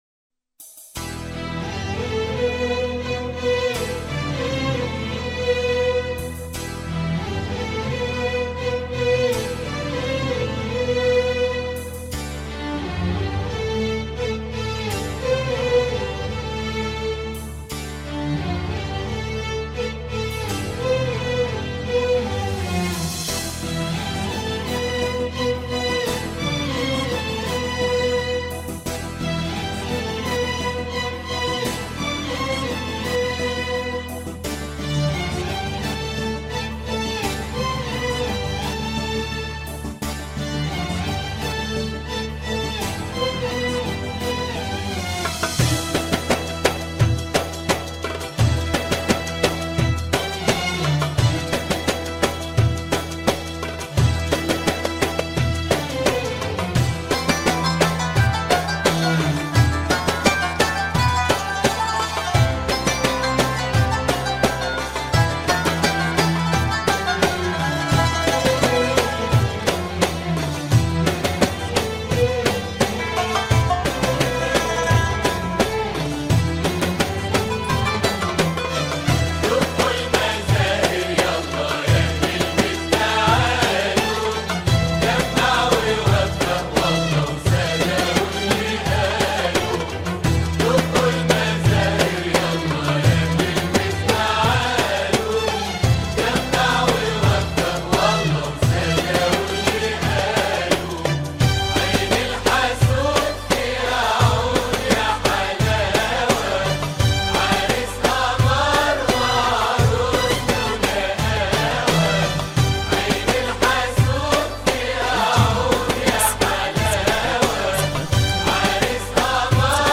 افخم زفه استعدايه 2024